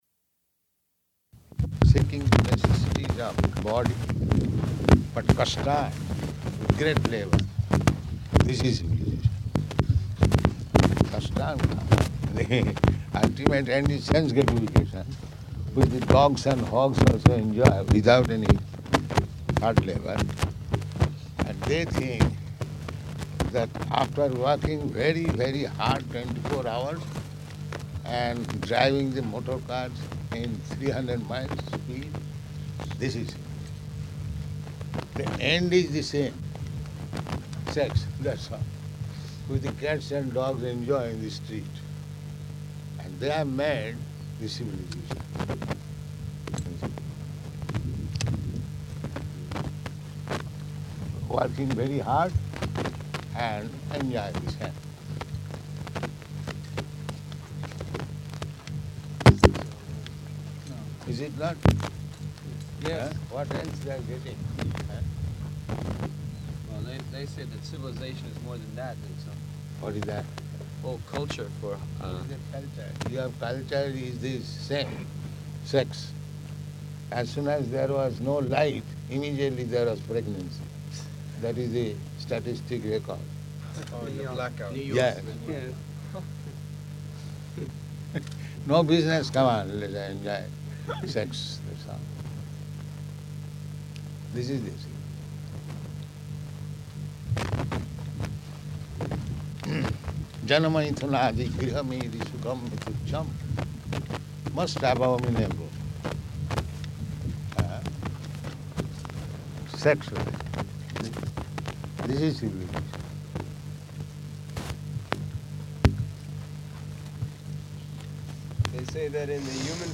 Morning Walk --:-- --:-- Type: Walk Dated: April 5th 1975 Location: Māyāpur Audio file: 750405MW.MAY.mp3 [bad recording] Prabhupāda: ...seeking the necessities of the body, but kāṣṭān, with great labor.